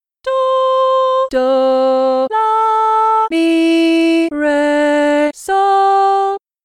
The melodies will use only simple steady rhythms so that you can focus on sight-singing the correct note pitches.
The melodies will use just do, re, mi, so, la, and do’.
Easy Melody 1: Sung